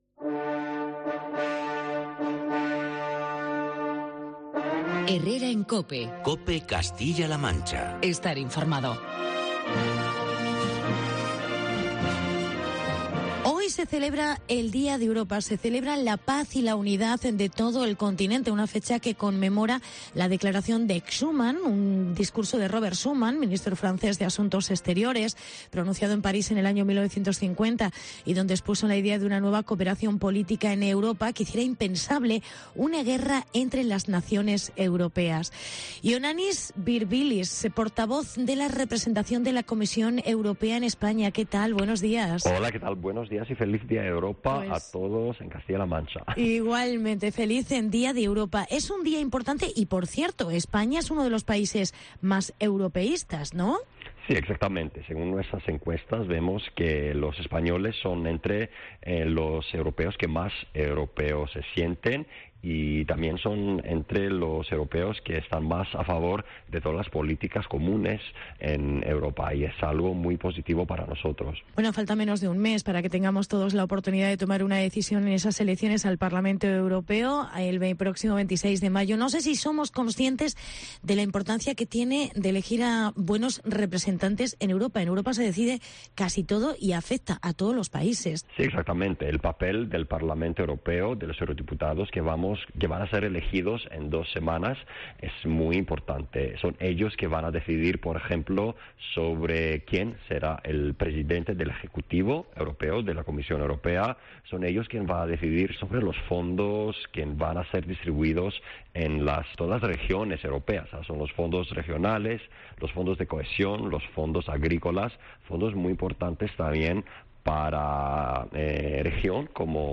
Celebración del Día de Europa en CLM. Entrevista